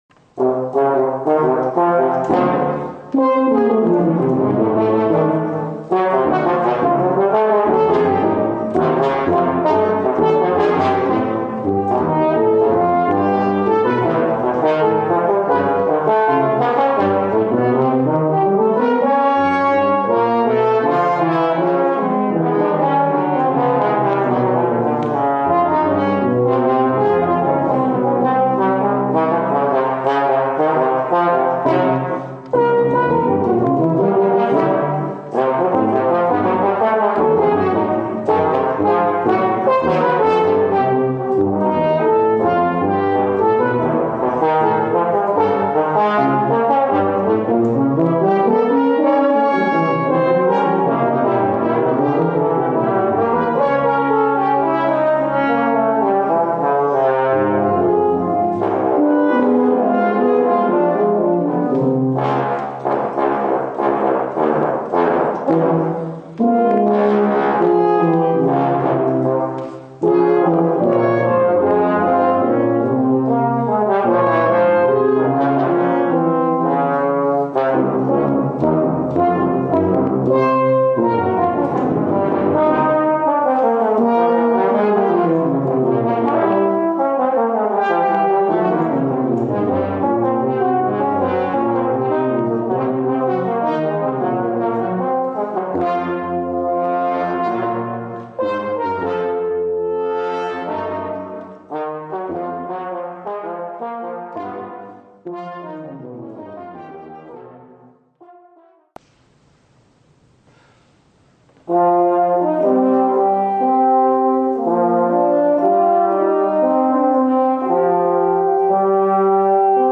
Voicing: Brass Trio